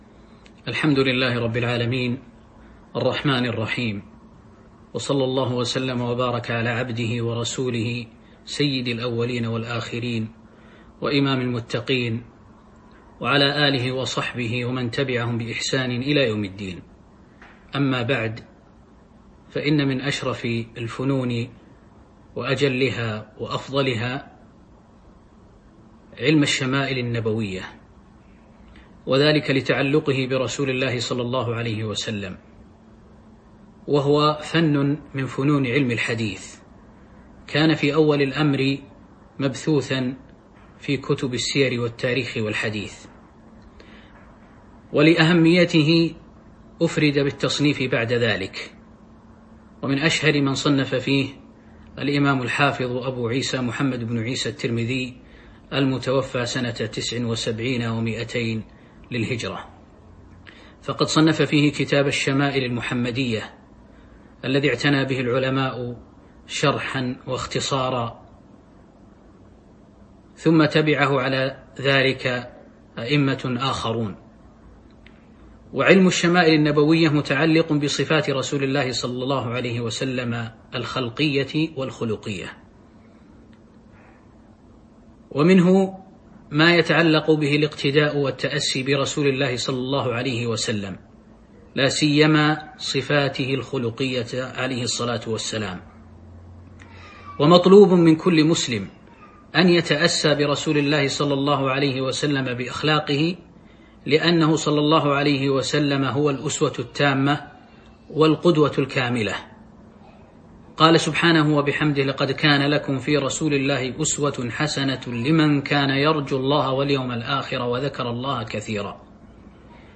تاريخ النشر ٢ شعبان ١٤٤٢ هـ المكان: المسجد النبوي الشيخ